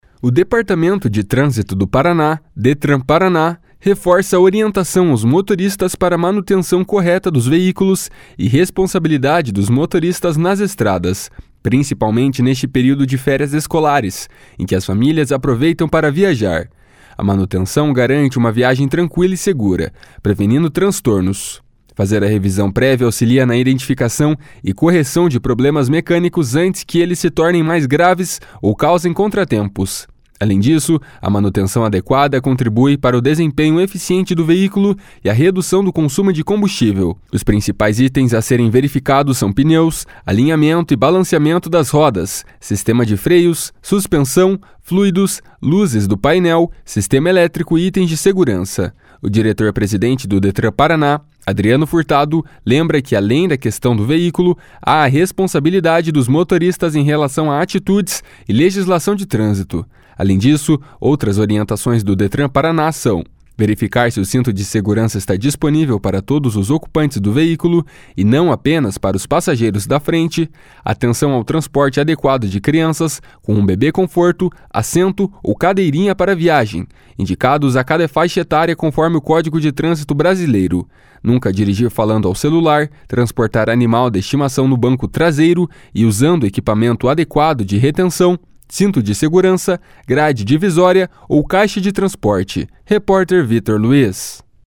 Os principais itens a serem verificados são pneus, alinhamento e balanceamento das rodas, sistema de freios, suspensão, fluidos, luzes do painel, sistema elétrico e itens de segurança. O diretor-presidente do Detran-PR, Adriano Furtado, lembra que, além da questão do veículo, há a responsabilidade dos motoristas em relação a atitudes e legislação de trânsito.